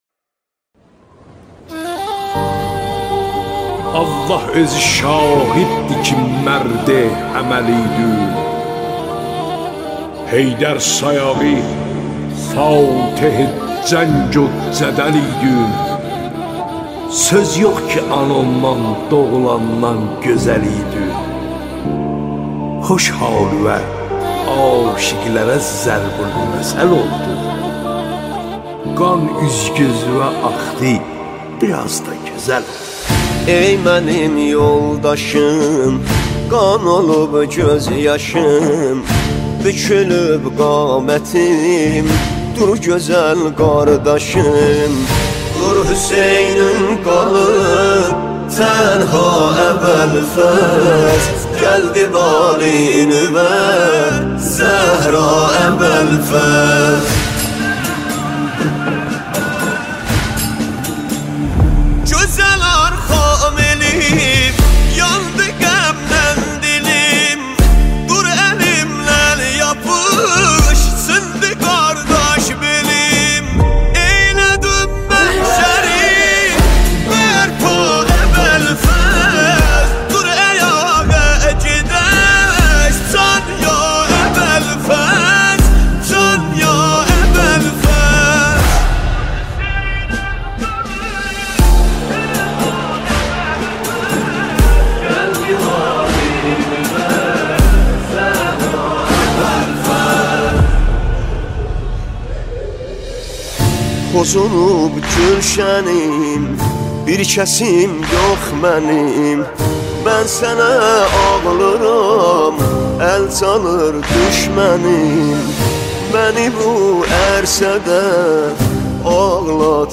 نماهنگ دلنشین ترکی